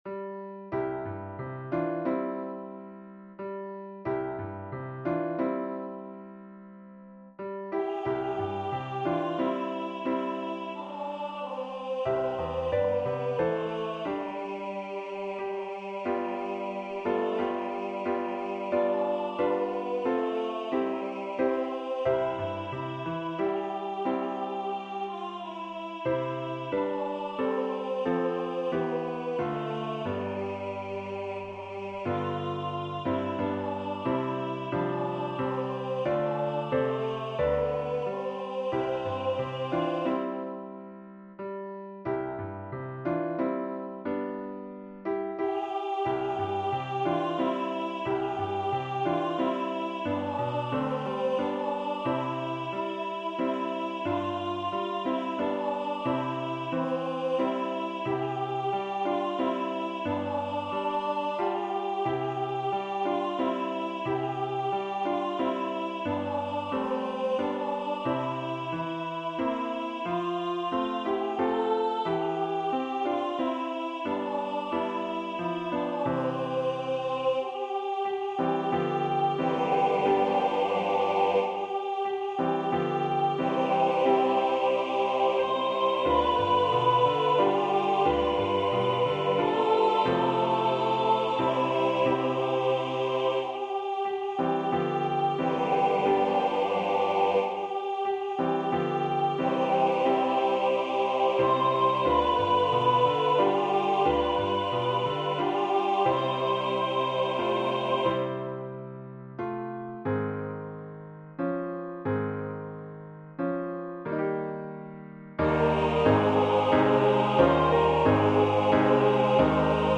Primary Children/Primary Solo, SATB
I recommend that all primary children and their primary teachers sing with the choir and piano with this arrangement. Very powerful, very spiritual and very inspiring.